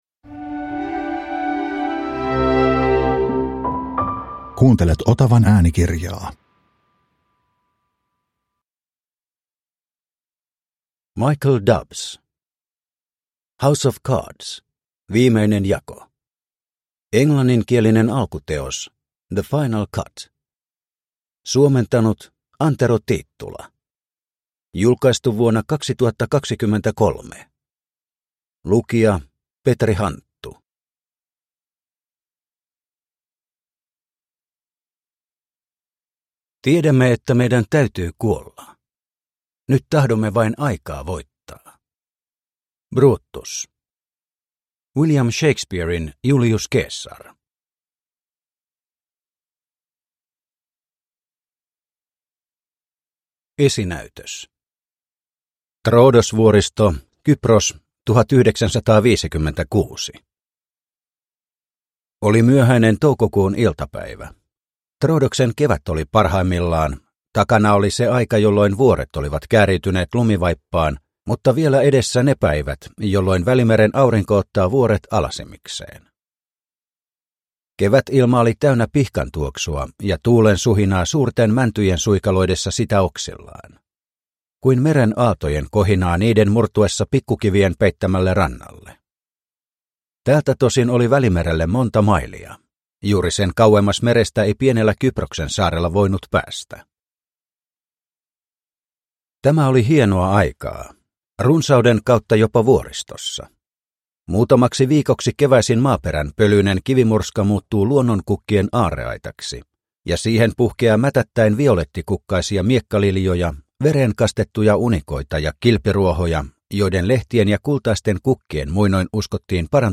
House of cards - Viimeinen jako – Ljudbok – Laddas ner